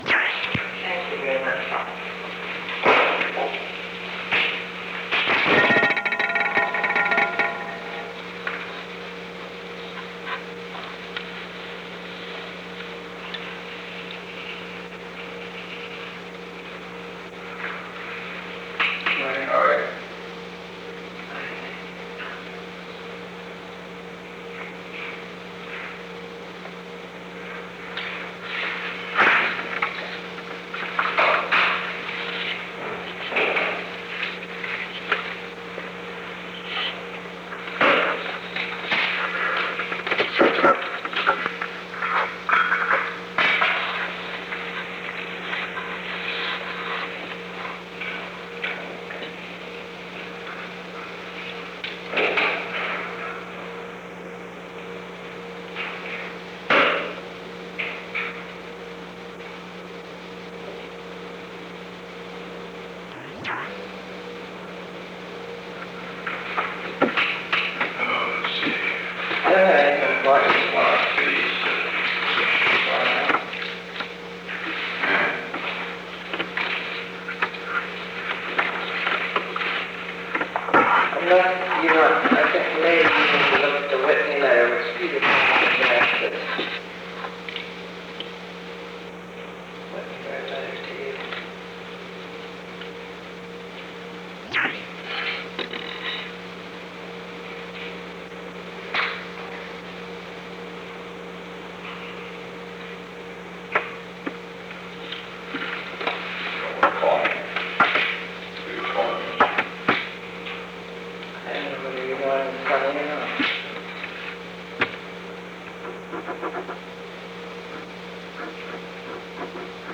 Secret White House Tapes
Location: Oval Office
The President met with Rose Mary Woods.